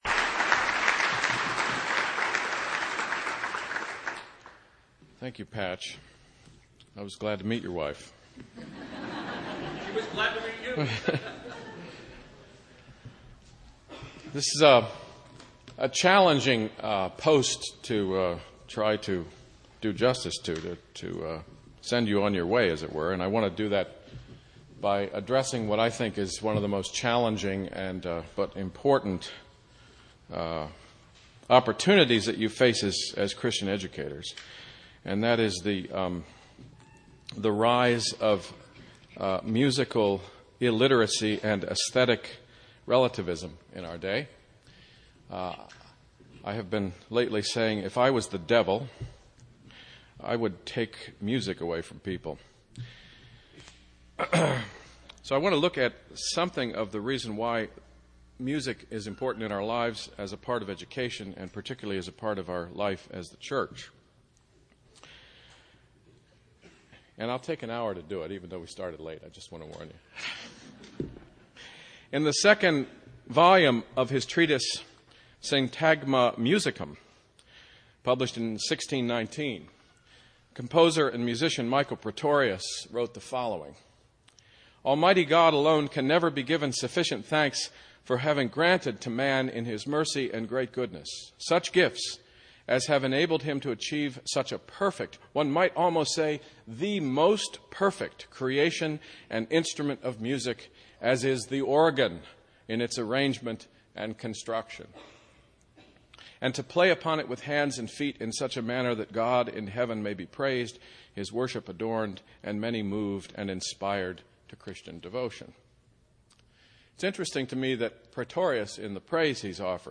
2008 Plenary Talk | 0:51:20 | All Grade Levels, Art & Music